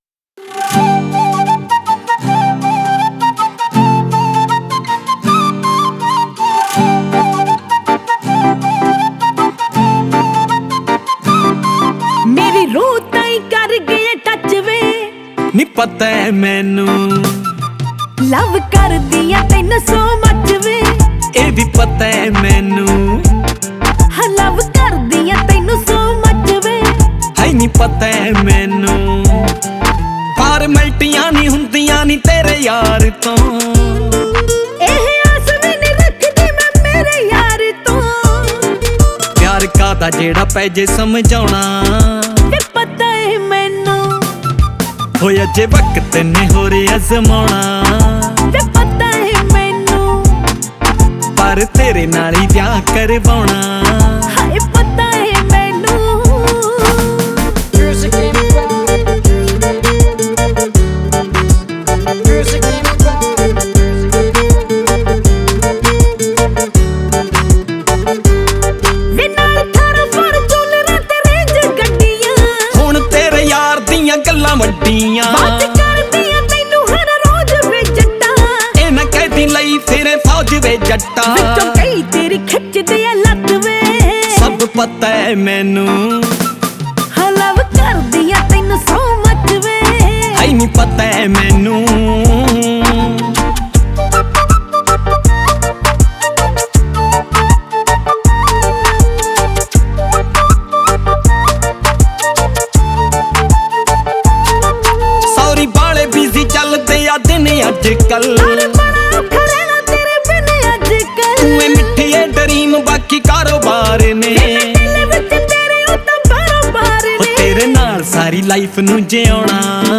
Punjabi Single Track song